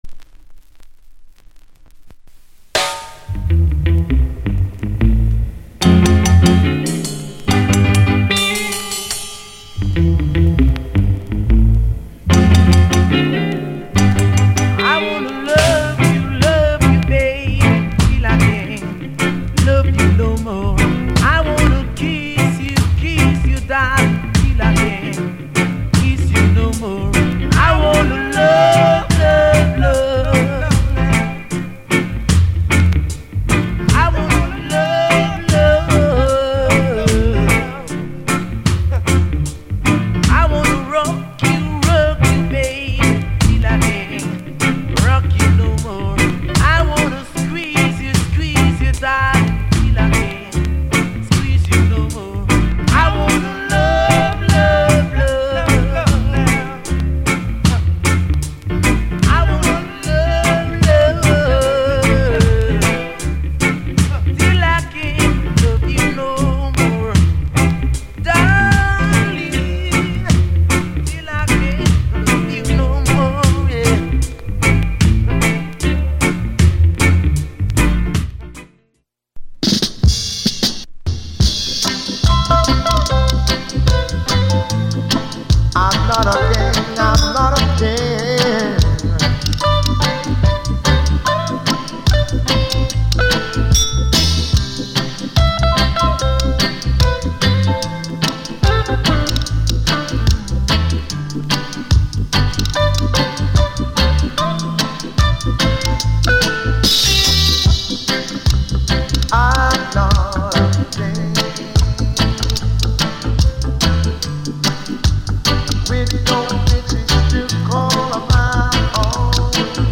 Male Vocal